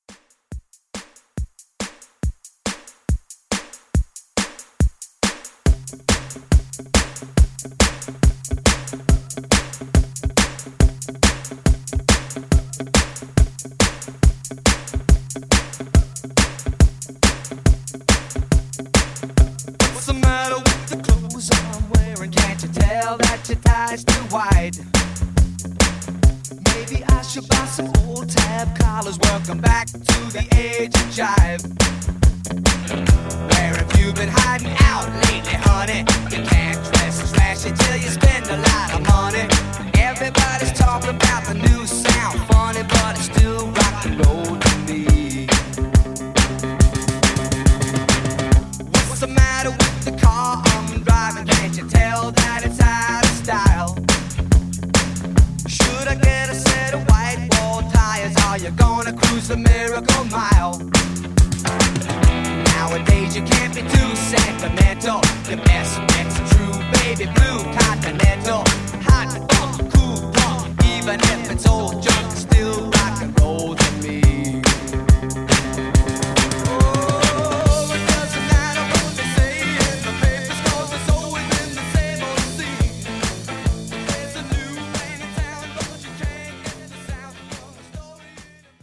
Genres: BOOTLEG , DANCE
Clean BPM: 124 Time